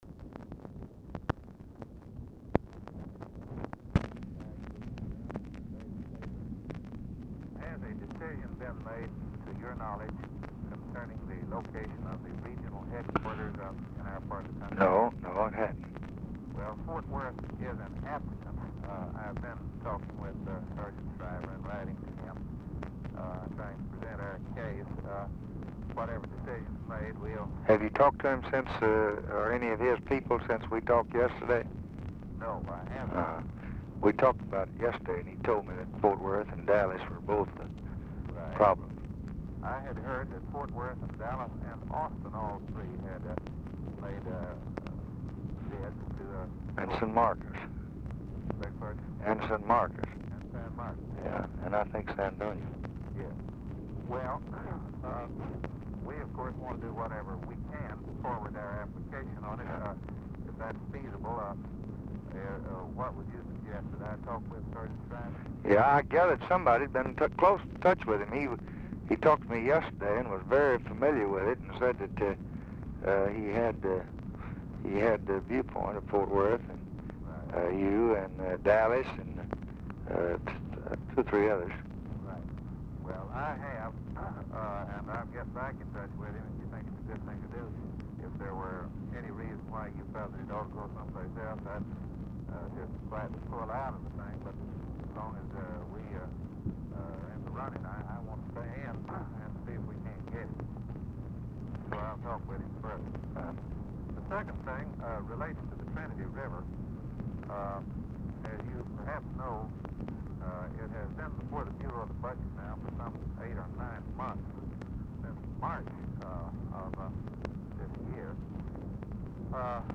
Telephone conversation # 6481, sound recording, LBJ and JIM WRIGHT, 11/25/1964, 7:29PM
RECORDING STARTS AFTER CONVERSATION HAS BEGUN; WRIGHT IS DIFFICULT TO HEAR
Format Dictation belt
LBJ Ranch, near Stonewall, Texas